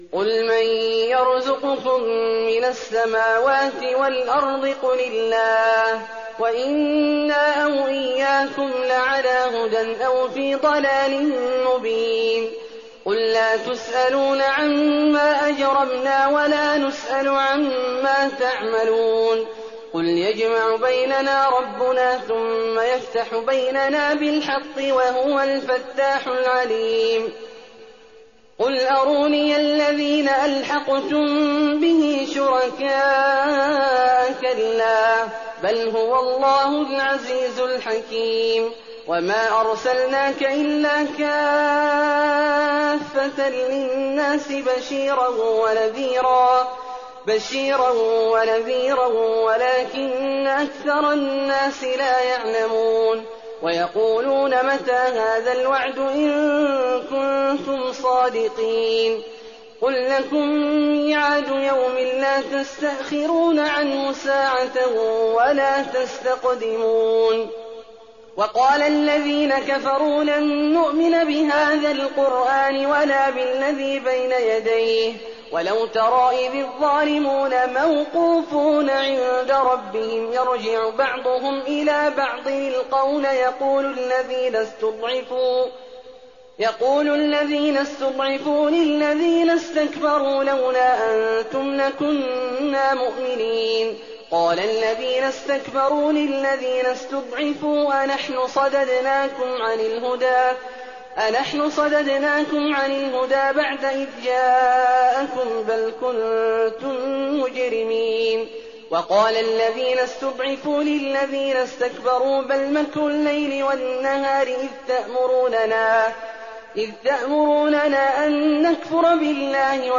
تراويح ليلة 21 رمضان 1419هـ من سور سبأ (24-54) و فاطر و يس (1-19) Taraweeh 21st night Ramadan 1419H from Surah Saba and Faatir and Yaseen > تراويح الحرم النبوي عام 1419 🕌 > التراويح - تلاوات الحرمين